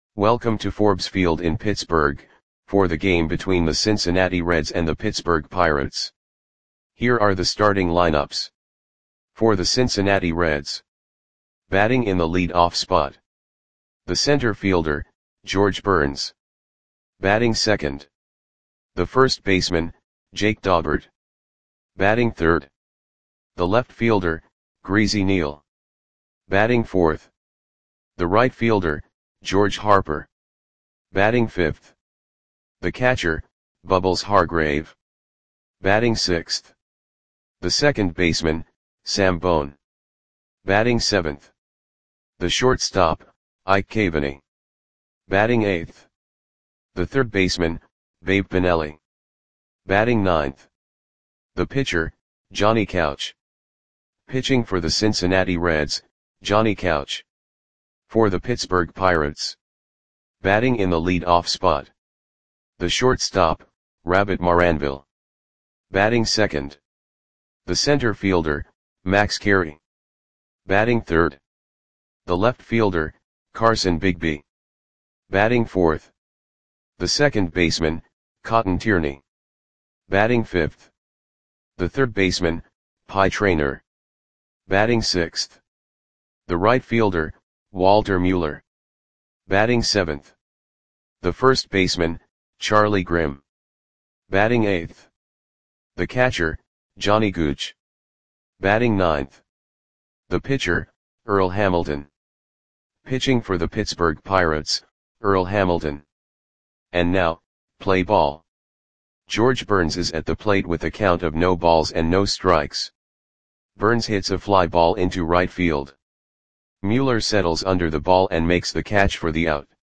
Audio Play-by-Play for Pittsburgh Pirates on May 31, 1922
Click the button below to listen to the audio play-by-play.